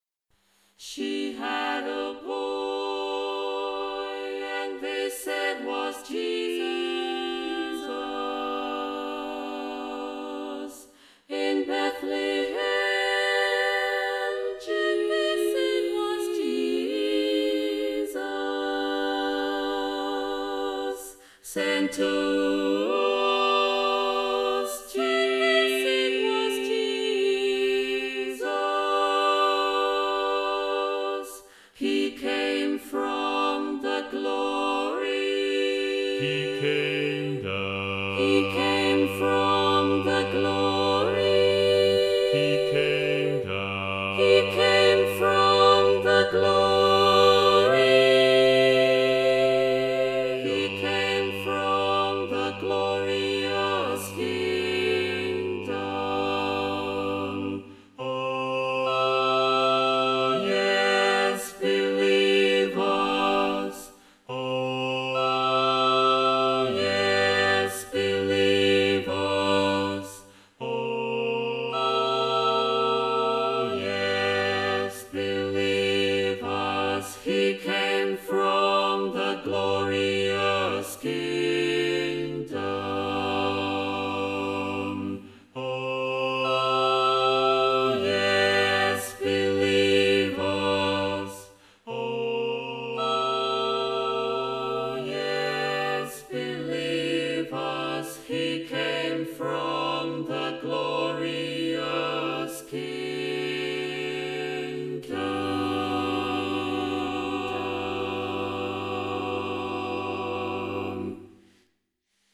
Number of voices: 4vv Voicing: SATB Genre: Sacred, Spiritual
Language: English Instruments: A cappella
Audio provided is Cantamus app virtual choir performance.